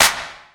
JJClap (24).wav